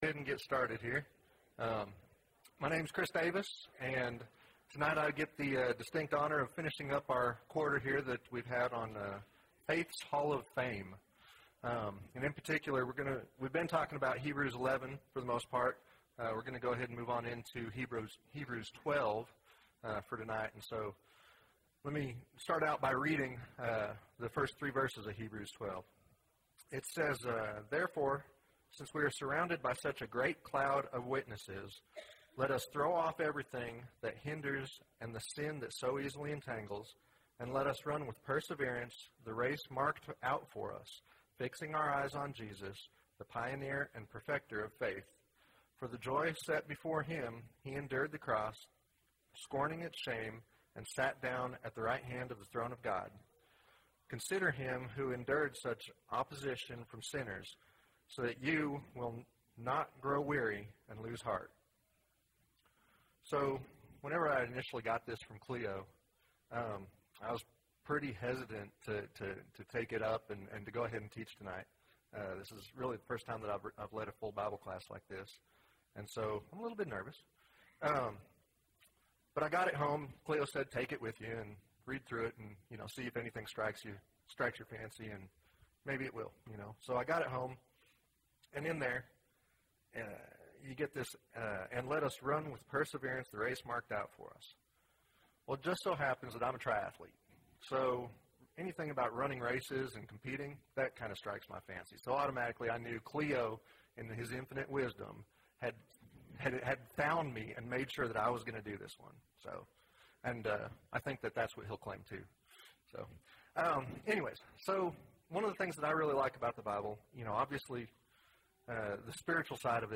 The Author and Perfecter of Our Faith (10 of 10) – Bible Lesson Recording